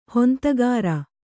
♪ hontagāra